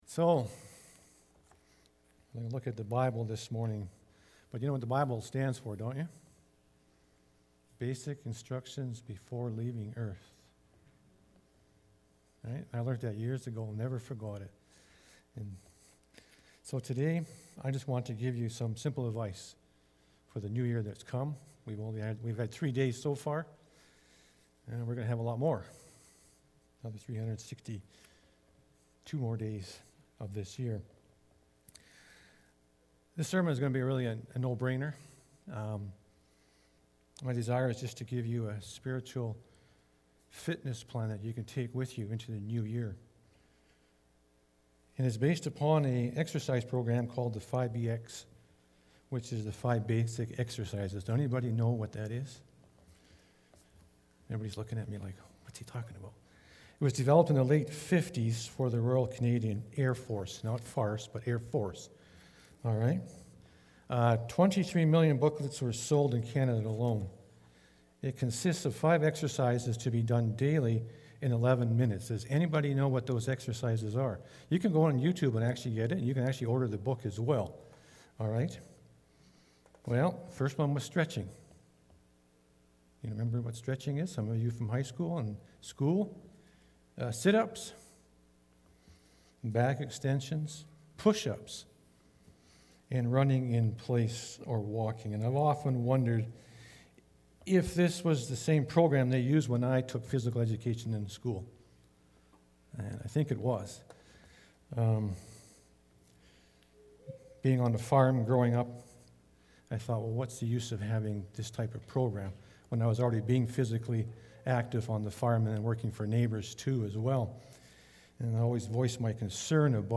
Jan-3-2021-Sermon.mp3